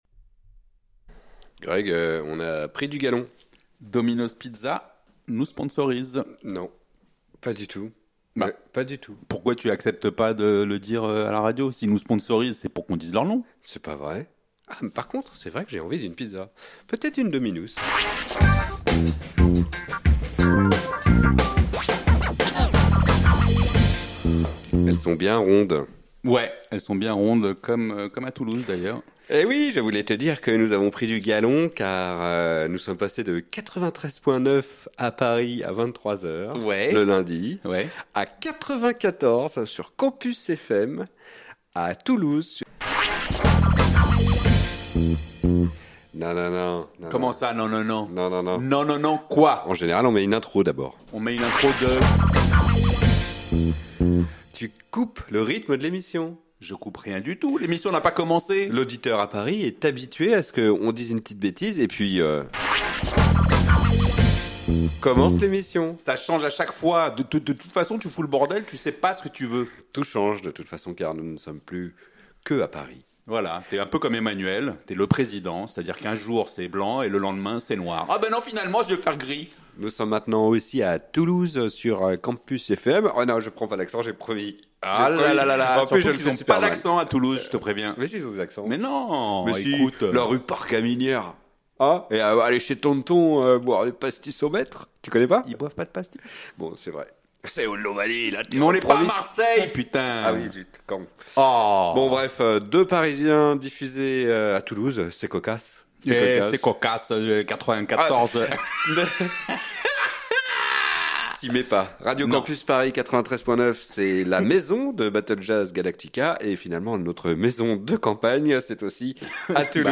Célébrant leur diffusion désormais toutes les semaines également sur les antennes de CampusFM à Toulouse (le mercredi à 18h), ils se lancent dans un concours cocasse de mauvais accents régionaux. Rassurez-vous, ils n'oublient pas se taper sur la tronche et de diffuser d'excellents morceaux de jazz.